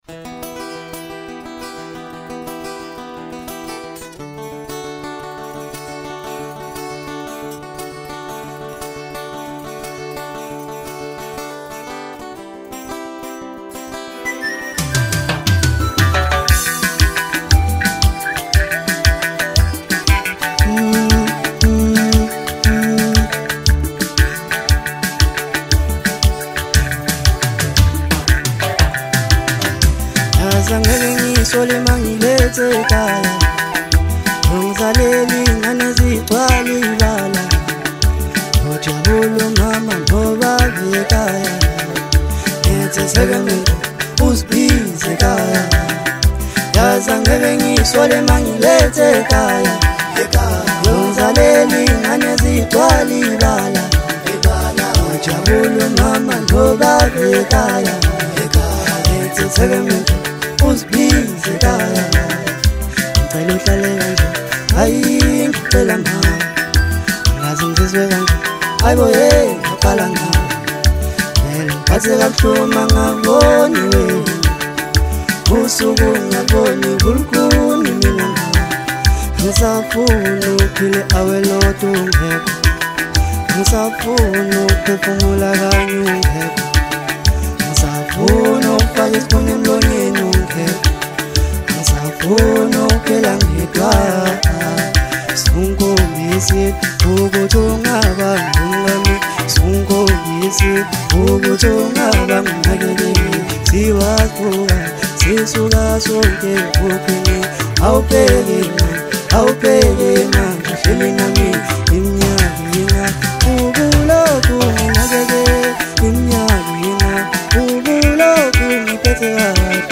Maskandi, DJ Mix, Hip Hop